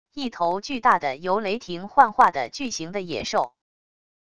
一头巨大的由雷霆幻化的巨型的野兽wav音频